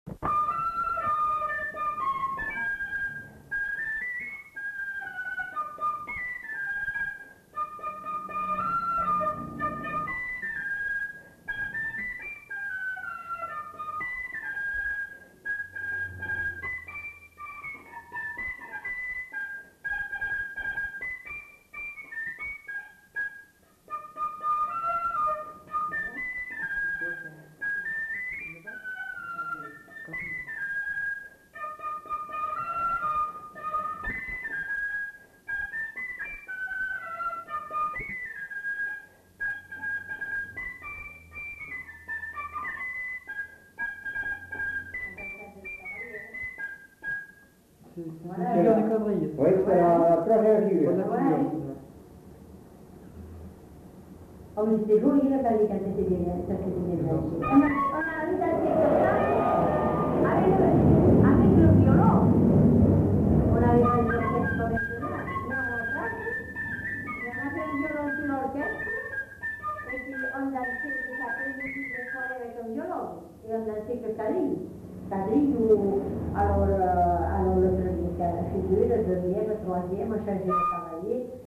Aire culturelle : Bazadais
Lieu : Bazas
Genre : morceau instrumental
Instrument de musique : fifre
Danse : quadrille (3e f.)